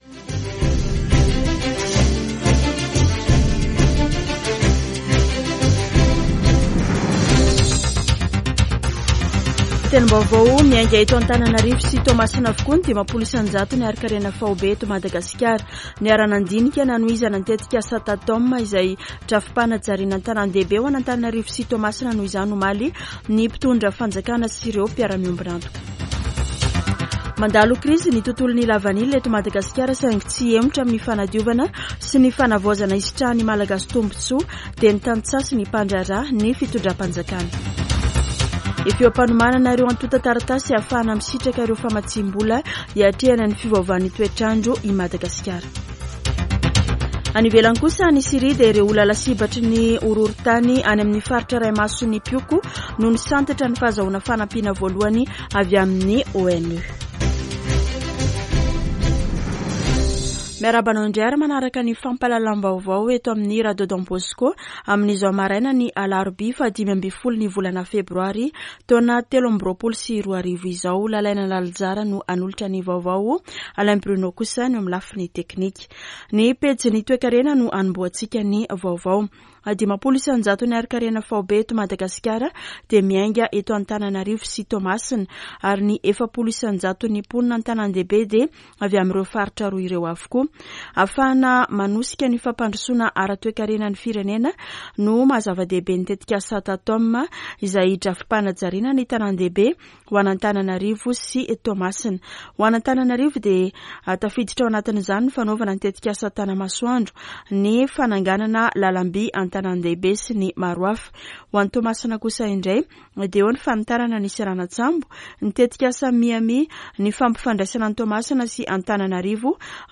[Vaovao maraina] Alarobia 15 febroary 2023